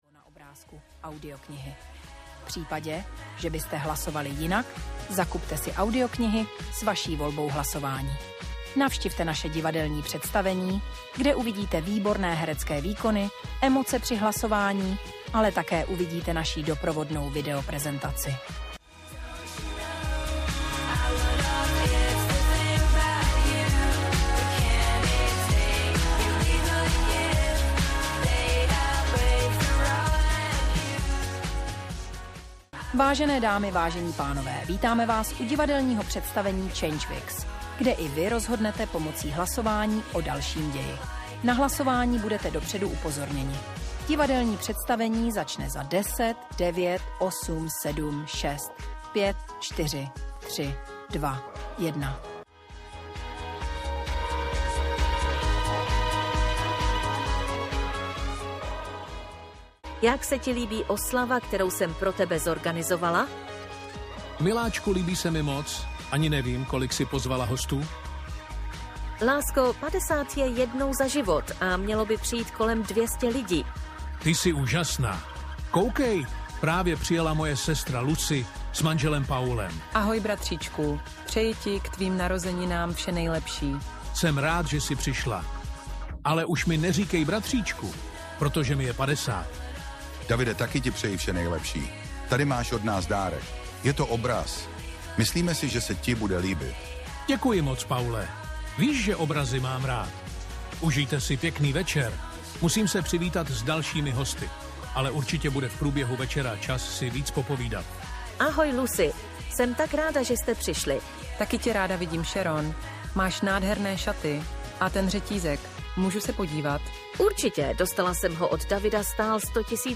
Ukázka z knihy
Při tvorbě audioknihy využil hlasy vytvořené umělou inteligencí.
• InterpretUmělá inteligence